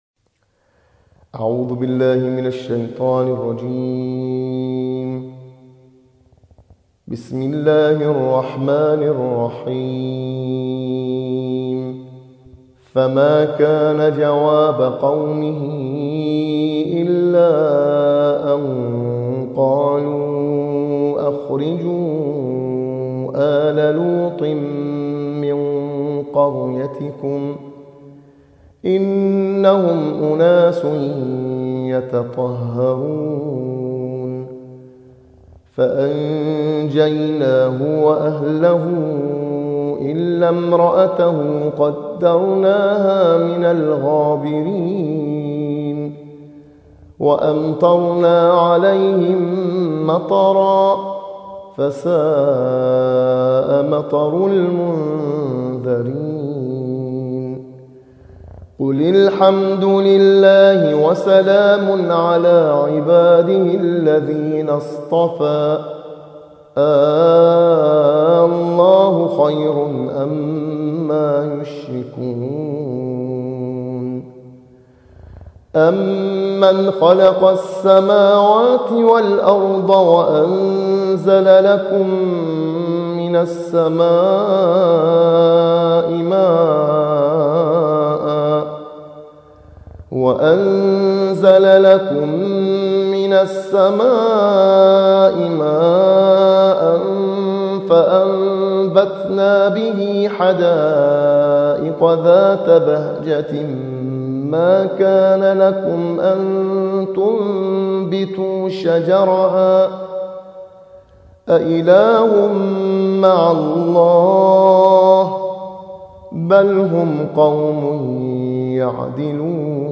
صوت | ترتیل‌خوانی جزء بیستم قرآن